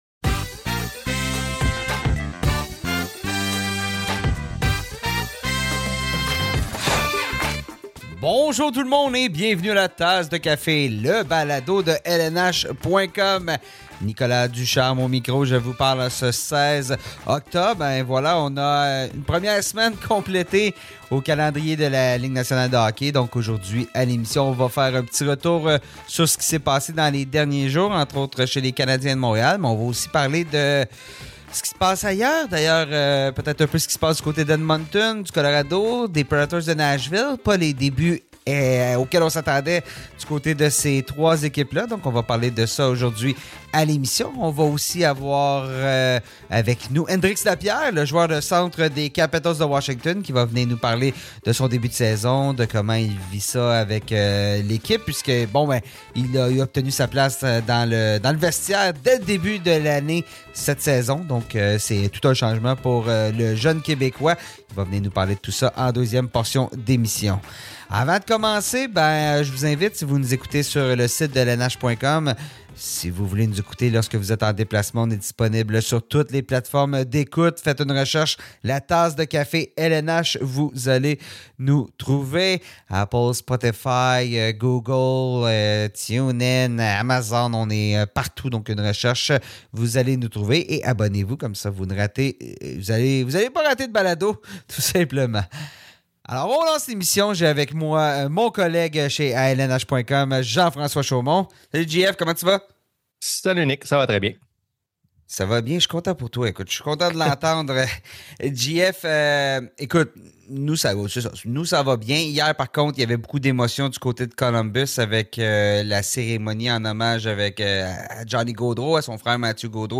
Entretien avec Hendrix Lapierre